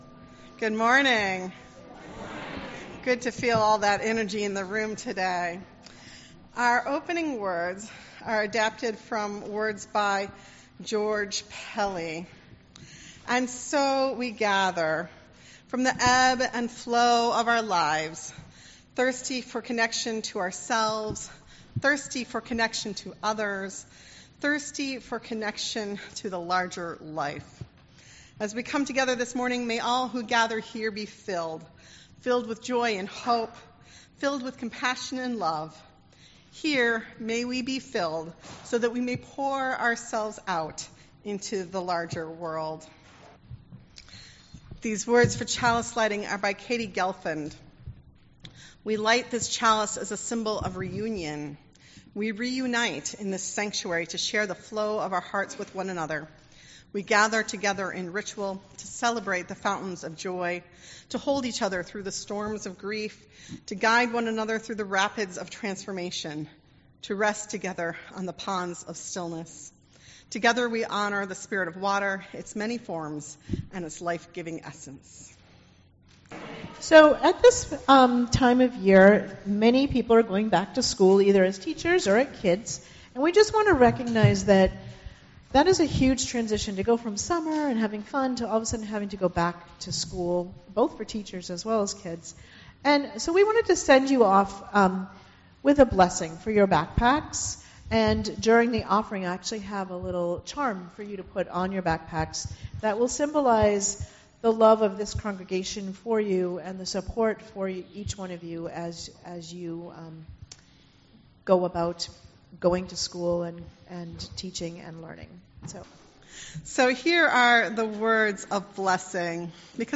Sunday, June 14th, 2020 "PRIDE CELEBRATION" Sanctuary closed. Virtual Service by video or phone or at 107.7 FM in the FPC parking lot.